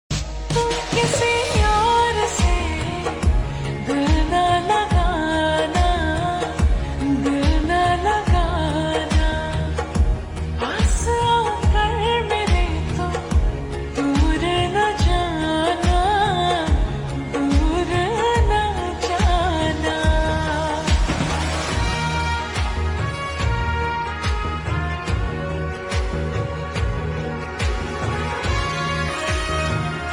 Hindi Songs
Slow Reverb Version
• Simple and Lofi sound
• Crisp and clear sound